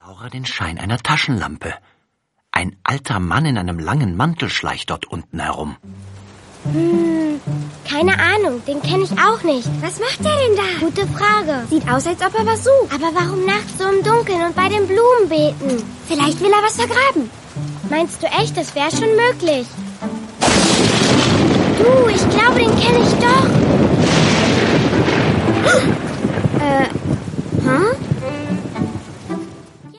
Hörbuch: Glitzernde Gutenacht-Geschichte
Hoerprobe-Lauras-Stern_Glitzernde_Gutenachtgeschichten.mp3